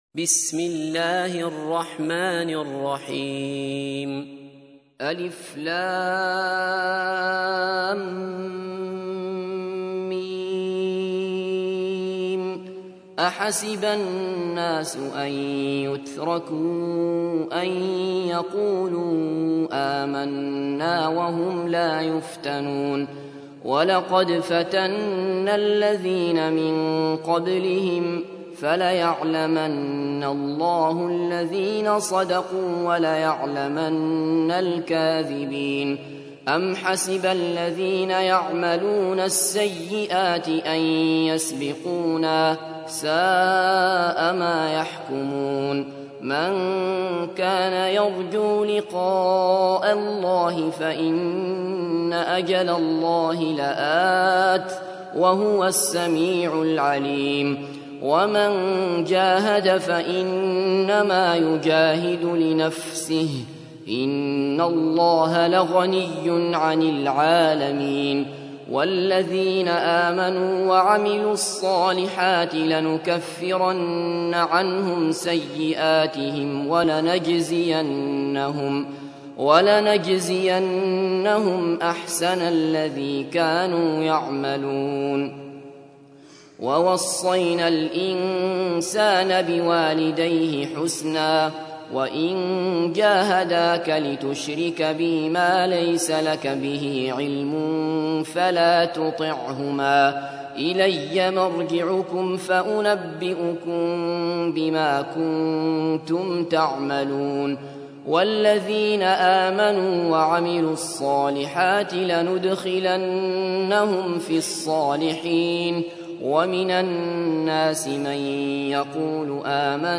تحميل : 29. سورة العنكبوت / القارئ عبد الله بصفر / القرآن الكريم / موقع يا حسين